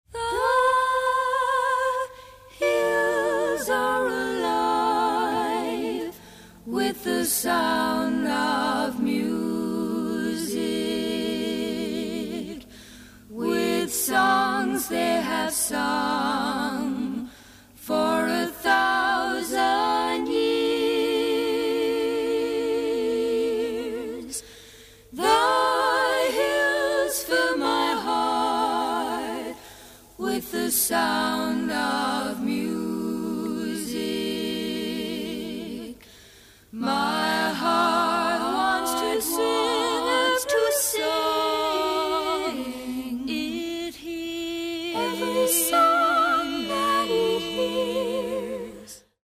an expansive medley
digitally remastered